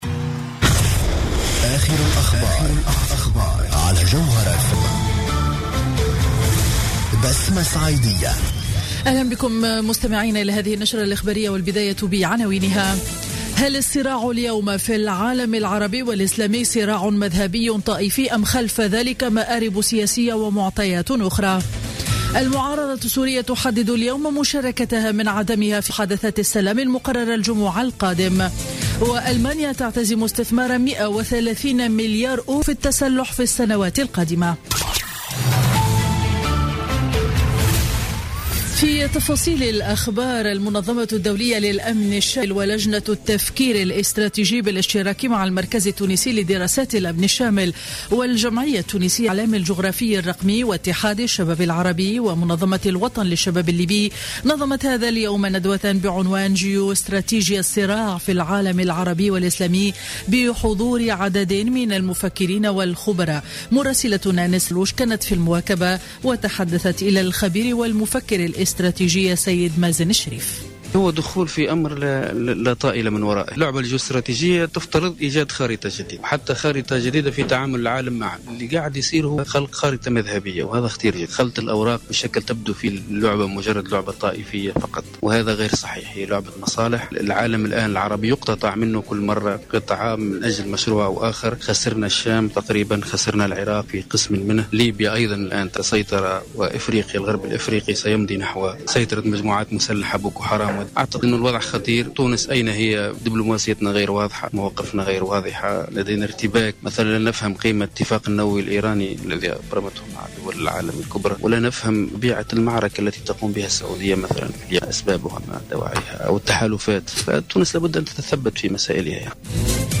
نشرة أخبار منتصف النهار ليوم الأربعاء 27 جانفي 2016